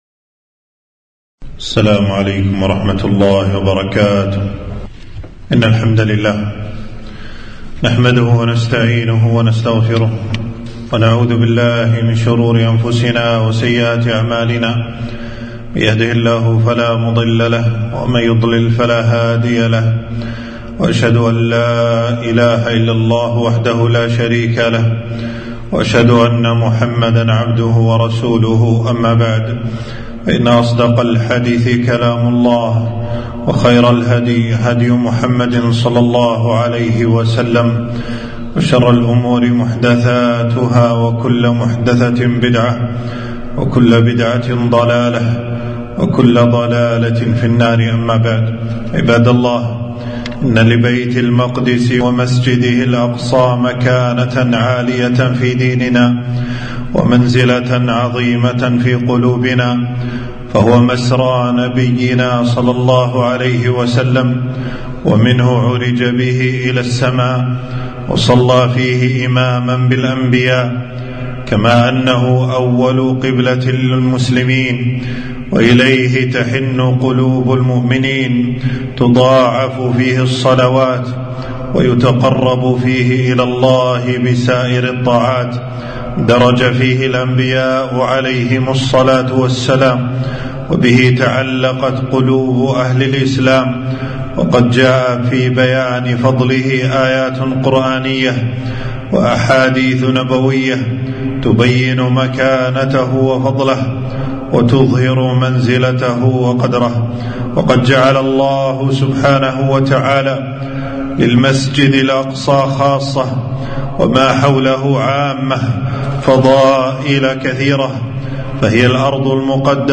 خطبة - المسجد الأقصى في قلب كل مسلم